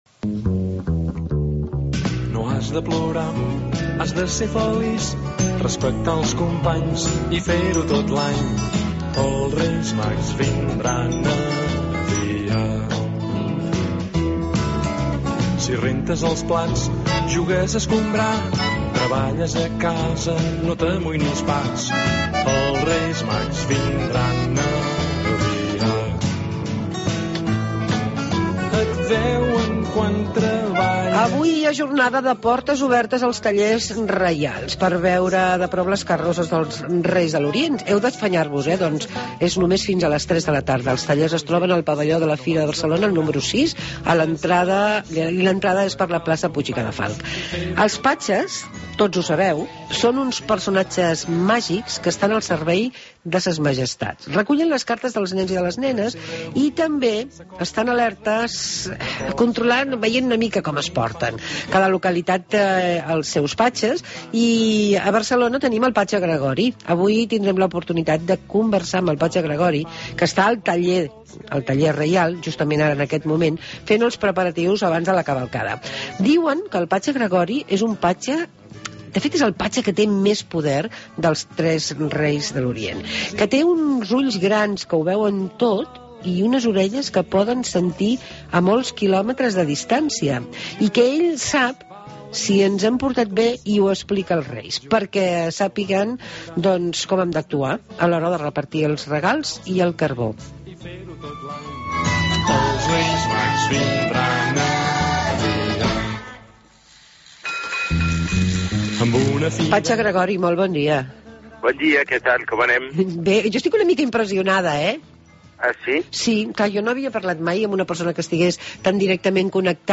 El Patge Gregori en directe a Tira Milles. T'has portat bé??